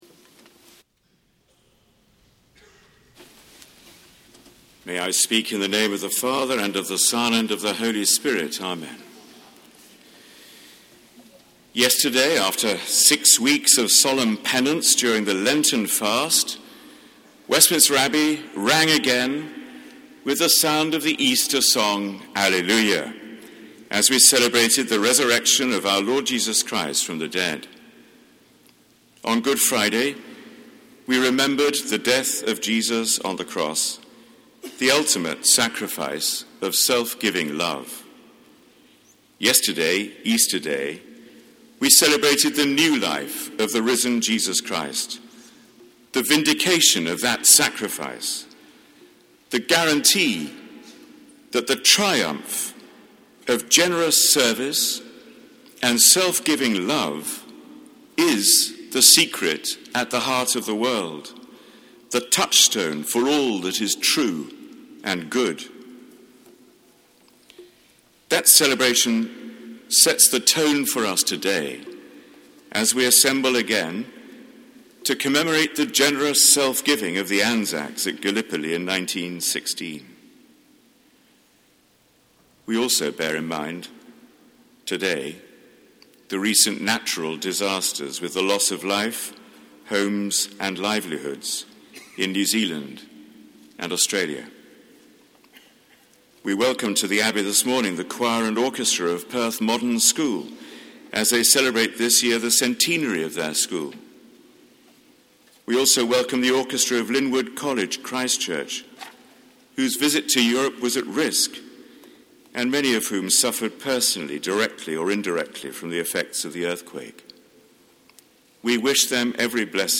Sermon given at a Service of Commemoration and Thanksgiving to mark ANZAC Day
Sermon given by the Very Reverend Dr John Hall, Dean of Westminster.